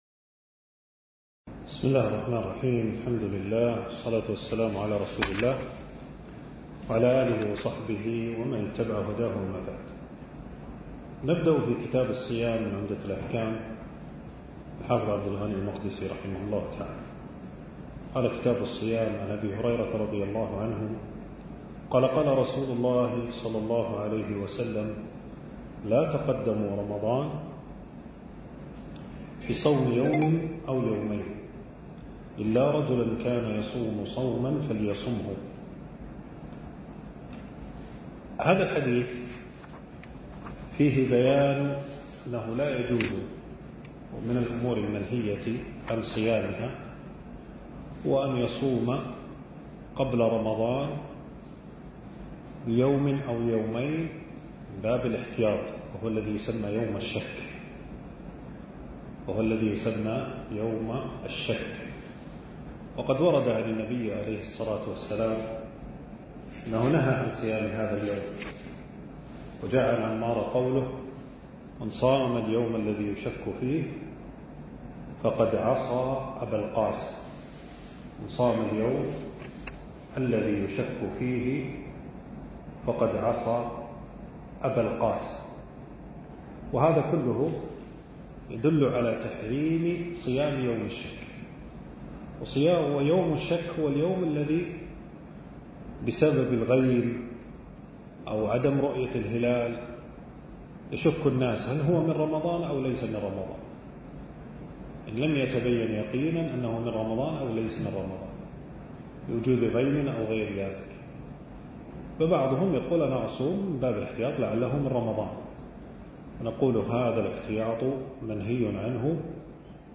المكان: درس ألقاه في 5 جمادى الثاني 1447هـ في مبنى التدريب بوزارة الشؤون الإسلامية.